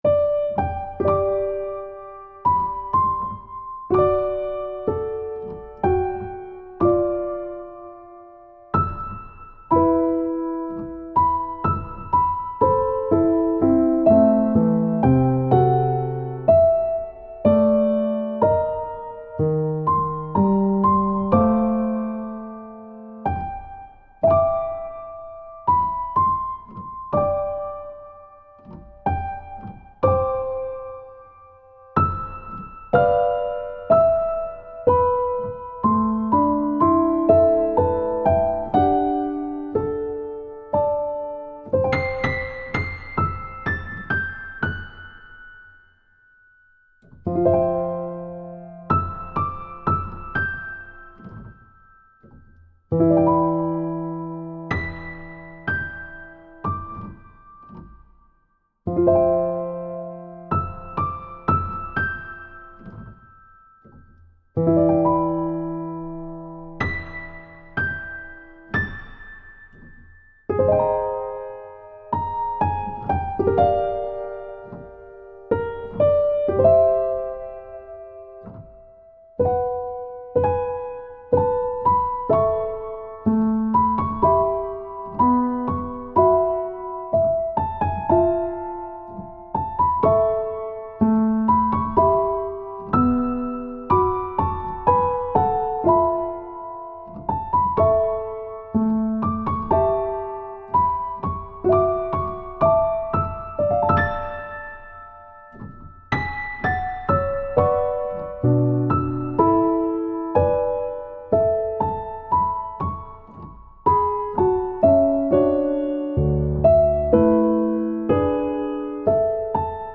物語の終焉を感じさせる、切なくて寂しいピアノ曲です。
BPM 62